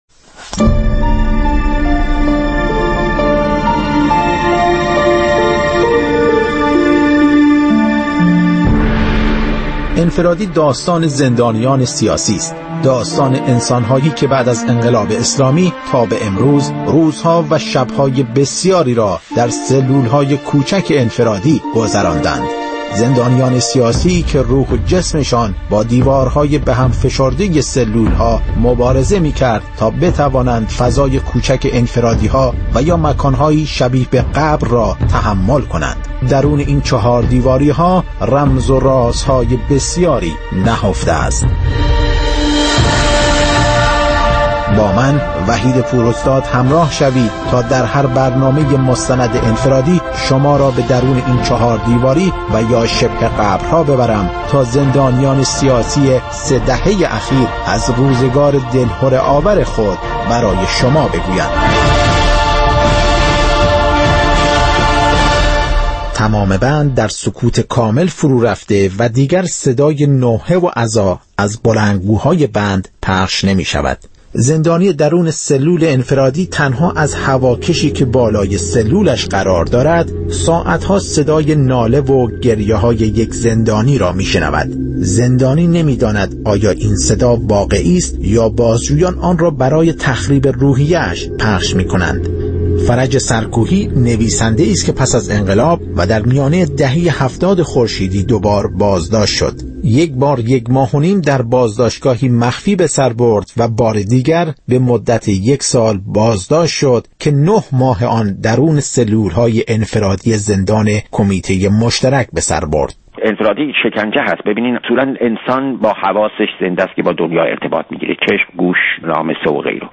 مستند رادیویی «انفرادی» هر هفته سه‌شنبه‌ها در ساعت ۱۸ به وقت ایران پخش می‌شود و روزهای پنجپنج‌شنبه ساعت ۱۴، روزهای جمعه ساعت ۹ صبح و یکشنبه‌ها ساعت ۲۳ به وقت ایران، تکرار می‌شود.